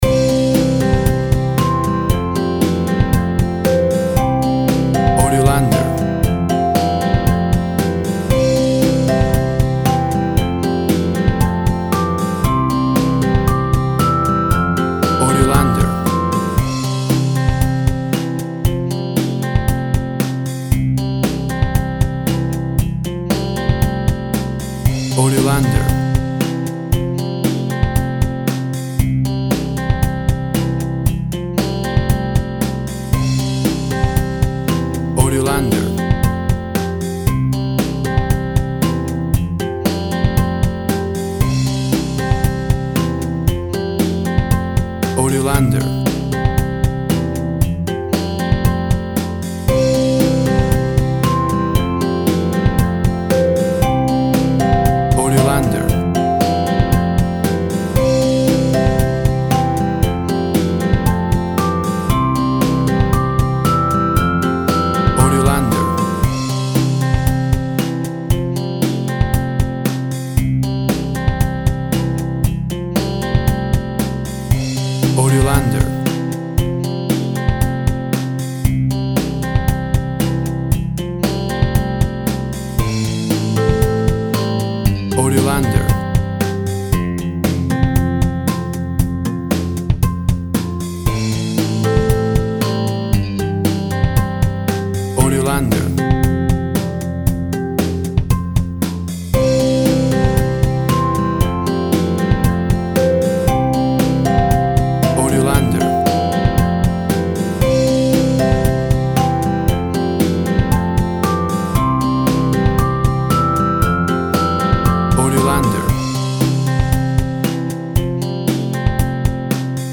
Tempo (BPM) 116